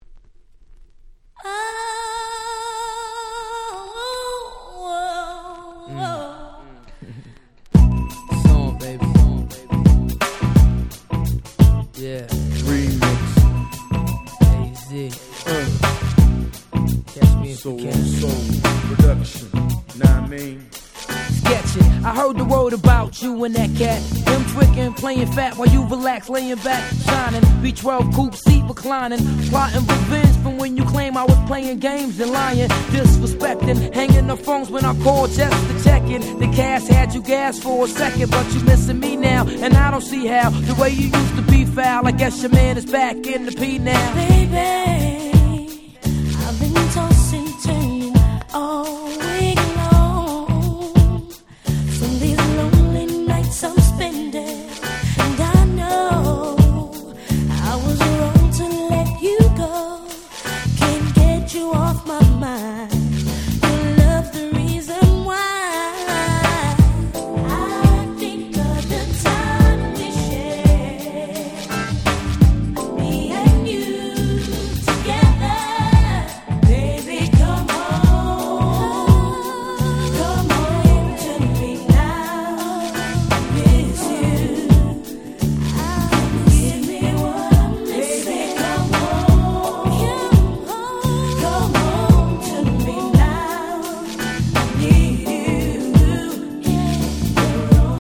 96' Smash Hit R&B !!
しっとりとしたHip Hop Soulで雰囲気は「ザ90's!!」って感じです。